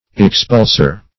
expulser - definition of expulser - synonyms, pronunciation, spelling from Free Dictionary Search Result for " expulser" : The Collaborative International Dictionary of English v.0.48: Expulser \Ex*puls"er\, n. An expeller.